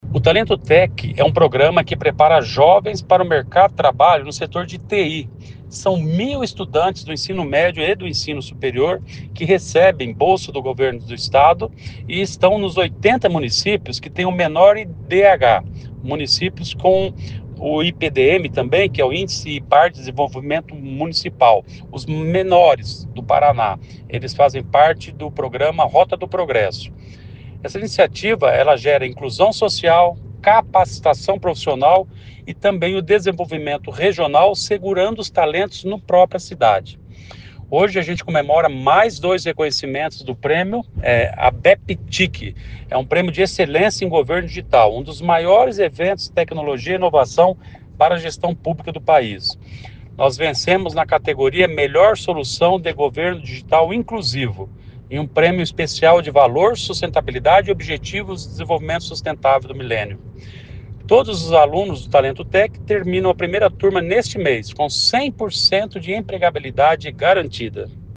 O secretário do Planejamento, Ulisses Maia, ressaltou o impacto da iniciativa.